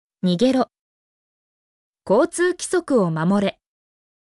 mp3-output-ttsfreedotcom-2_EykeM95C.mp3